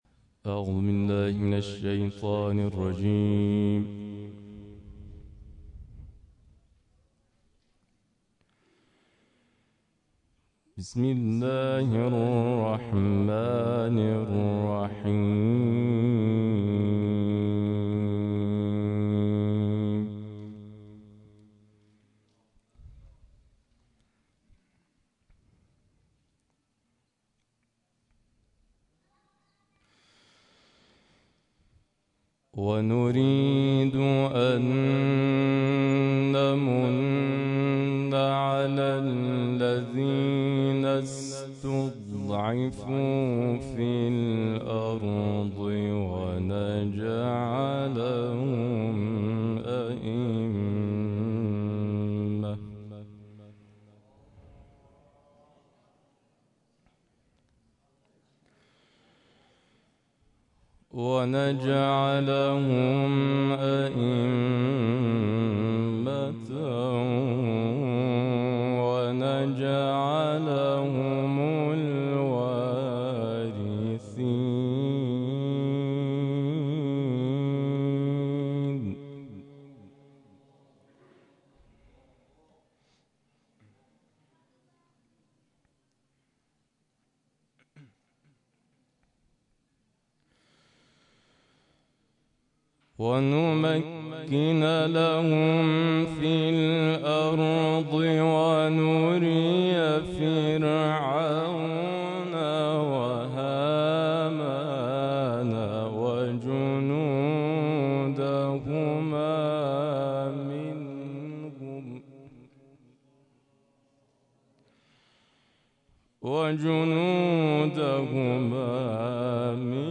دویست و پنجاه و هفتمین کرسی تلاوت نفحات‌القرآن در مسجد الغدیر تهرانسر برگزار شد
این تلاوت را بشنوید و آن را از بخش پیوست همین خبر دانلود کنید.